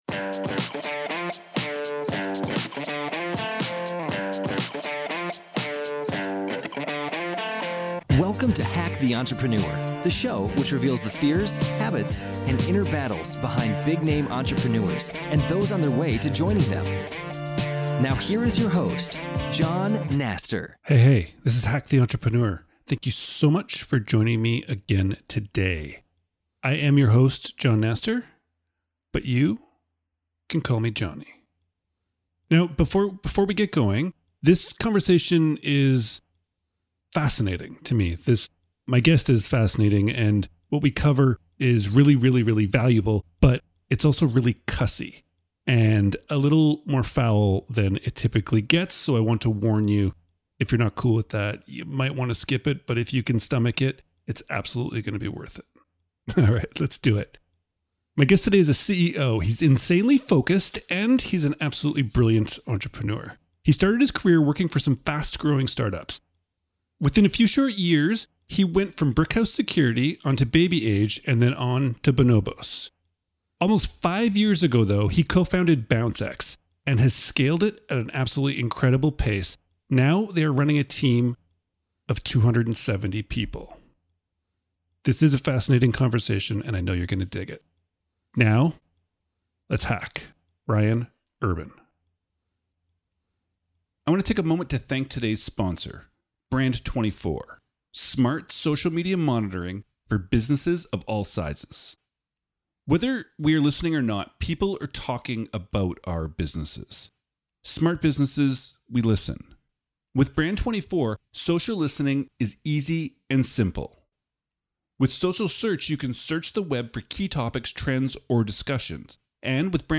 This is a fascinating conversation about how to hustle and acquire your first customers, drunk tests for employees, and understanding frustration zero.